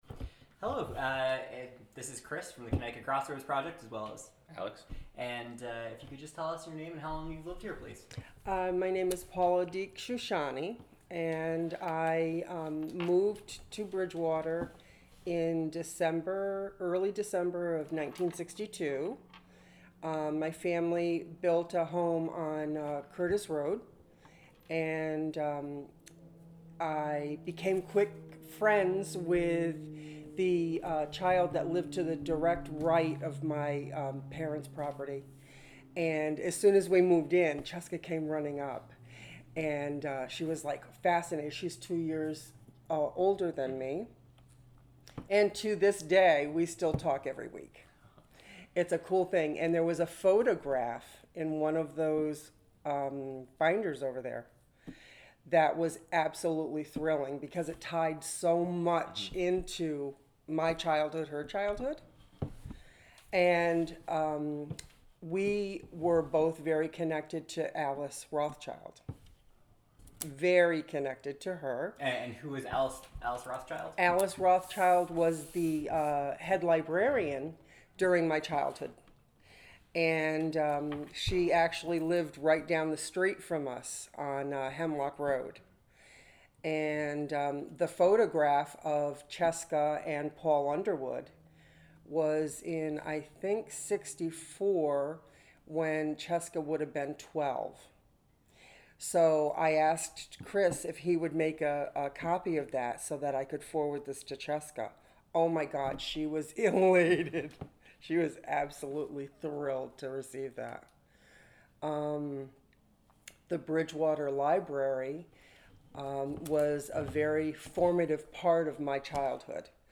Oral History
Burnham Library, Bridgewater, CT